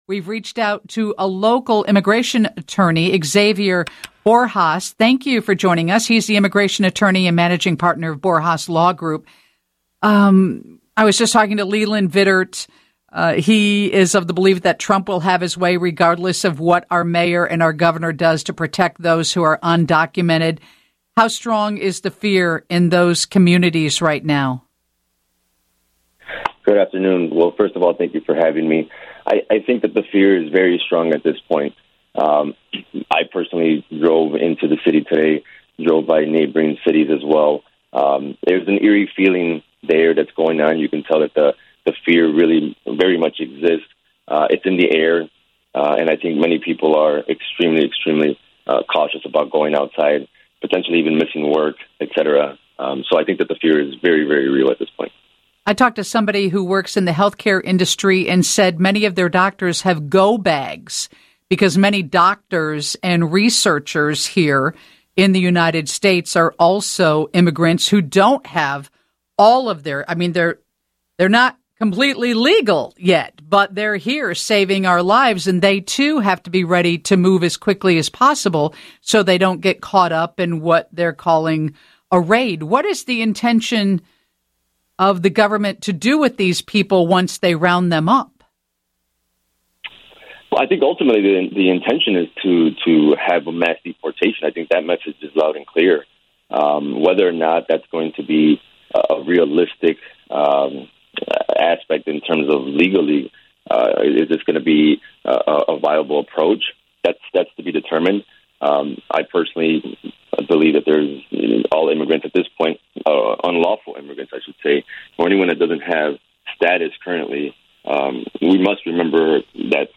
Immigration attorney on the potential Chicago ICE raids